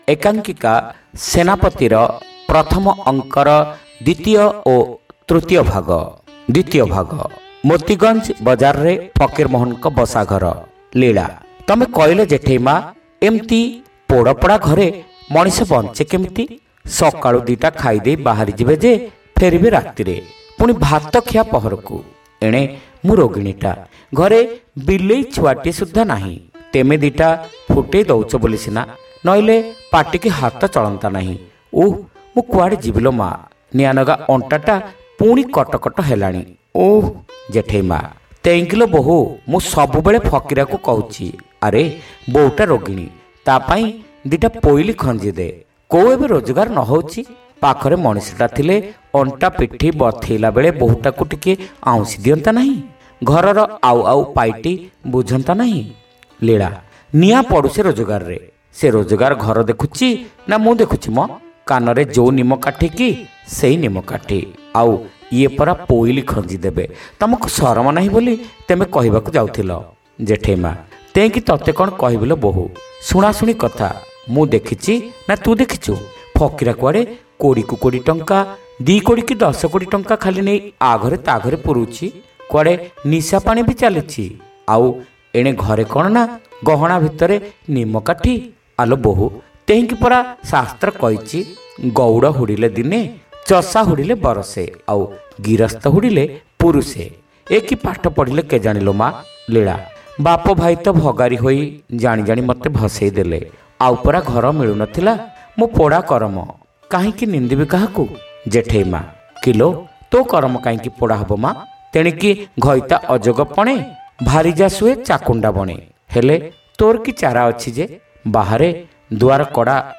ଶ୍ରାବ୍ୟ ଏକାଙ୍କିକା : ସେନାପତି (ଦ୍ୱିତୀୟ ଭାଗ)